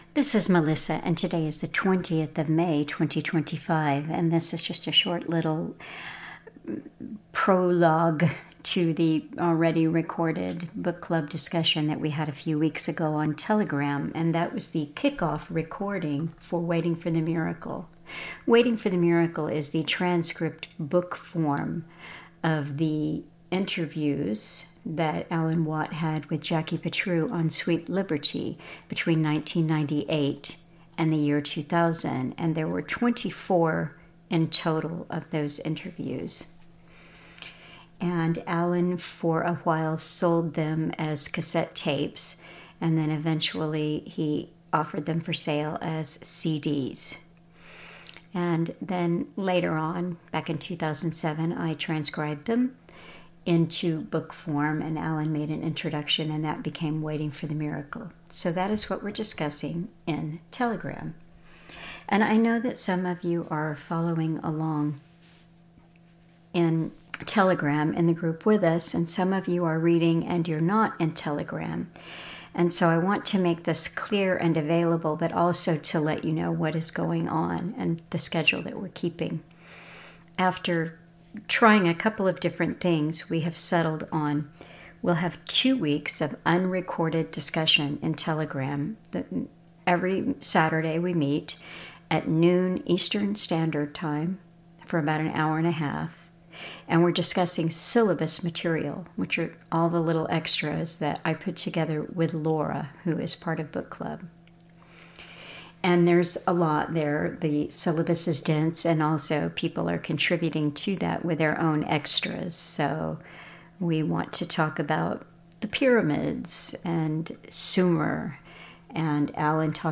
Educational Talk